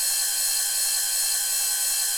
soft-sliderwhistle.wav